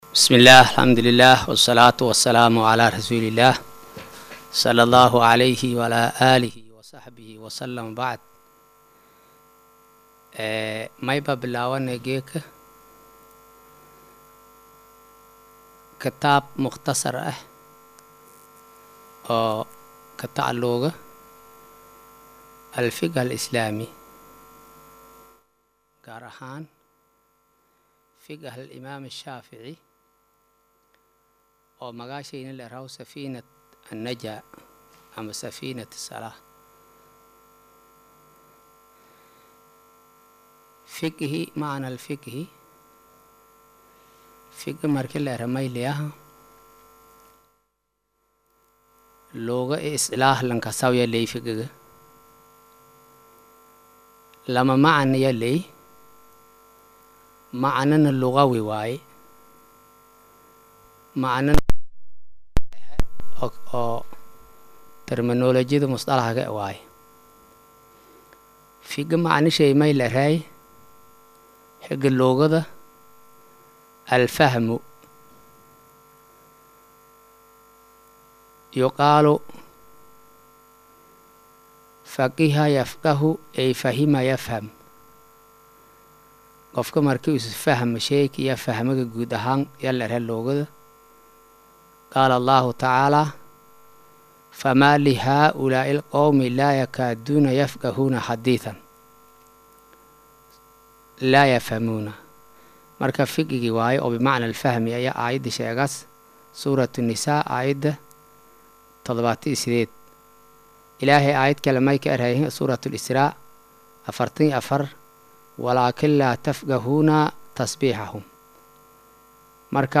Maqal:- Casharka Safiinatu Najaa “Darsiga 1aad”
casharka-1aad-ee-safiinatu-najaa.mp3